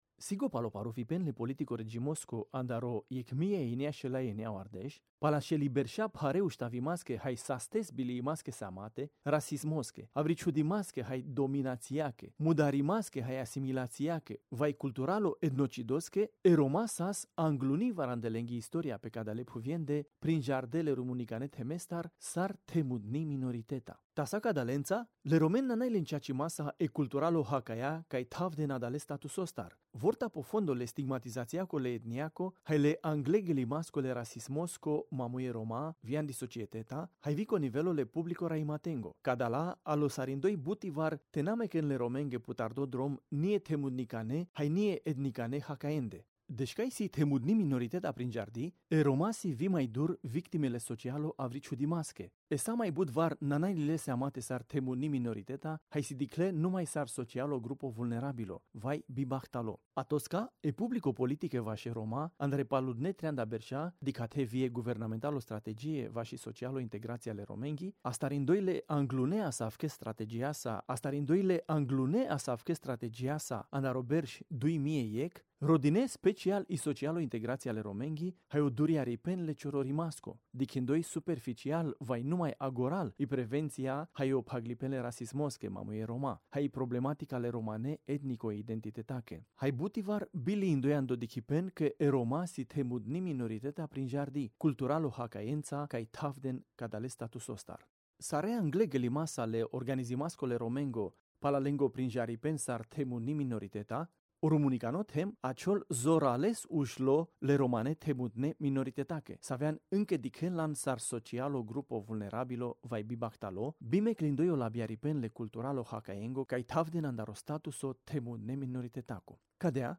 Audiobook-RR-Sp-6.mp3